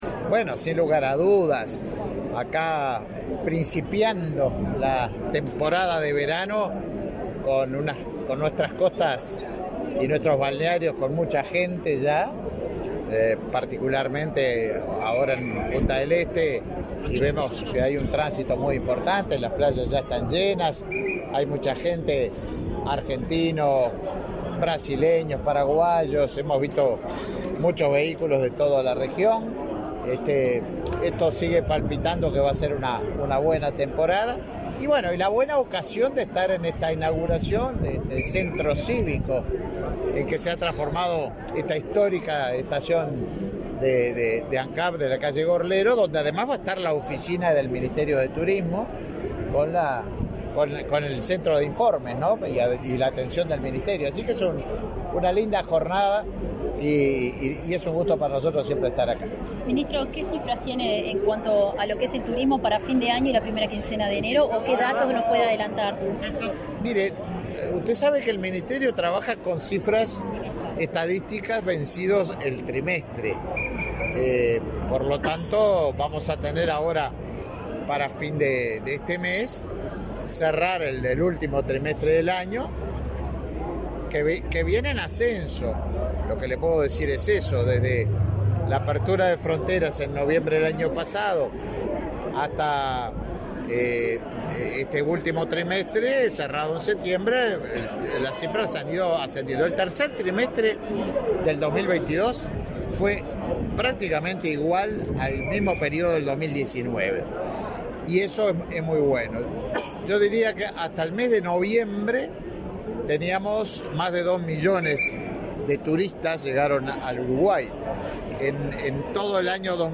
Declaraciones a la prensa del ministro de Turismo, Tabaré Viera
Declaraciones a la prensa del ministro de Turismo, Tabaré Viera 29/12/2022 Compartir Facebook X Copiar enlace WhatsApp LinkedIn Este 28 de diciembre fue inaugurado el Espacio Gorlero en vieja estación Ancap de Gorlero, en Punta del Este. En el evento participó el ministro de Turismo, Tabaré Viera.